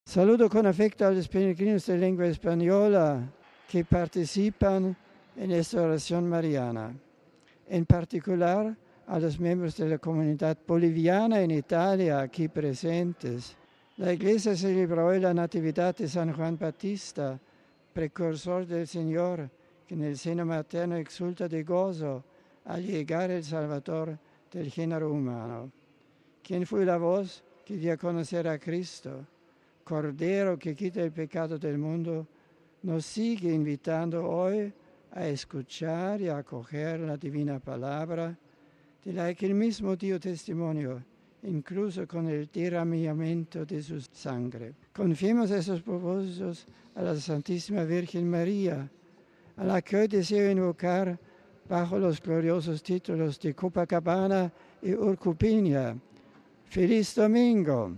Estos mismos peregrinos al mediodía escucharon la reflexión de Benedicto en italiano y sus saludos en diversas lenguas.
Estas fueron las palabras del Papa a los peregrinos de lengua española: (AUDIO) RealAudio